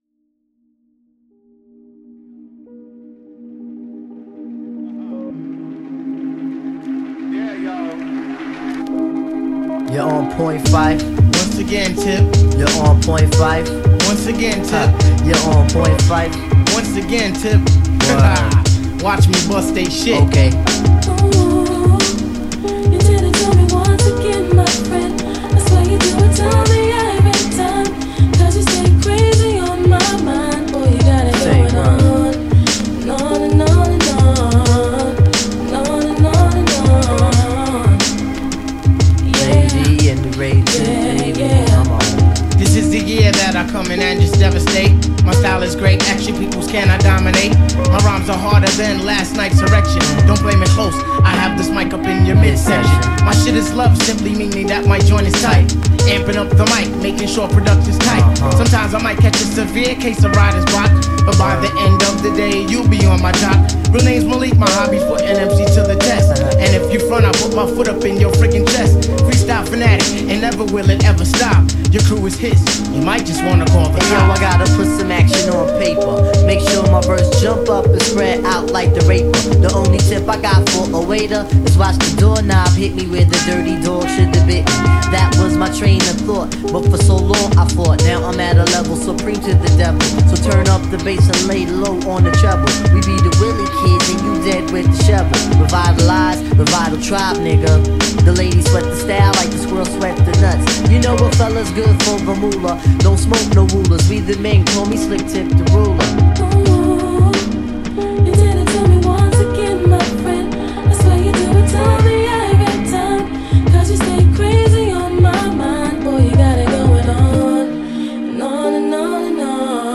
Here is my beat !
Anyway, this what i made on the digitakt using only provided samples (Acapalla added in reaper).